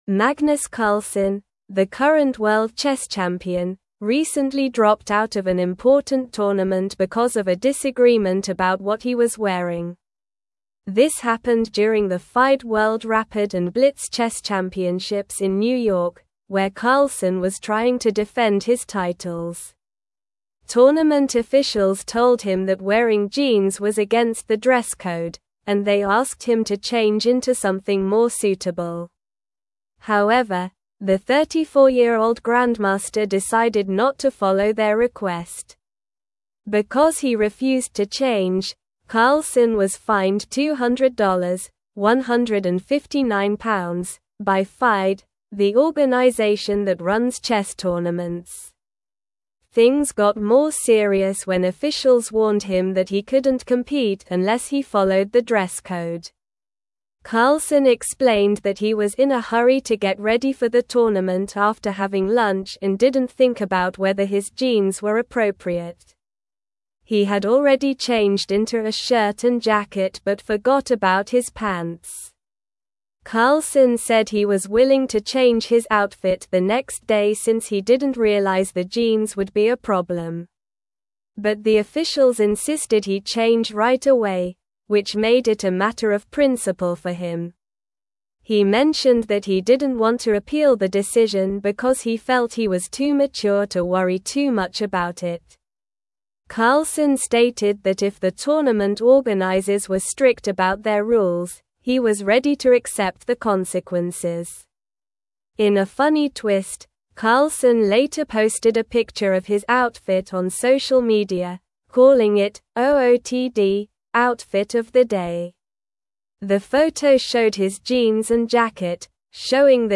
Slow
English-Newsroom-Upper-Intermediate-SLOW-Reading-Carlsen-Withdraws-from-Tournament-Over-Dress-Code-Dispute.mp3